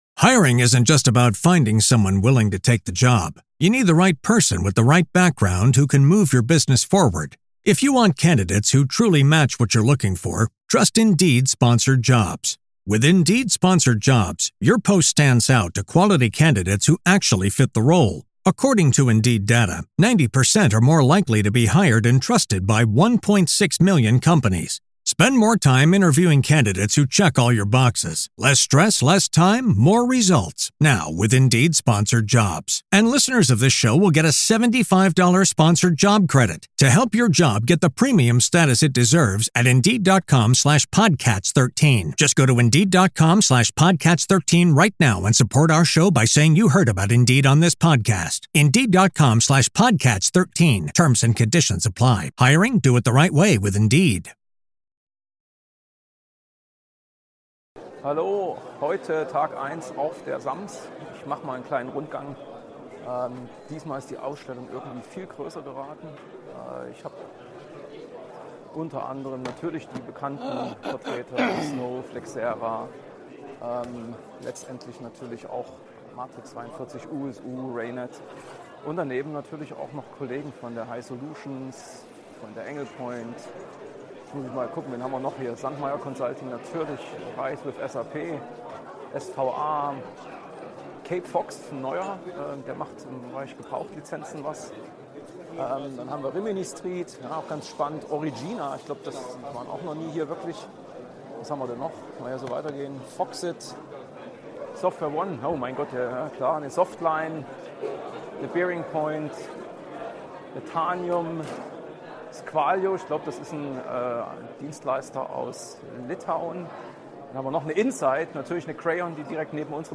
Der SAMS Award ging an die HDI und TimeToAct und ich bin völlig aus dem Häuschen. Meine Stimme ist beschlagen und in wenigen Minuten geht es mit einer Präsentation zum Thema RISE with SAP weiter.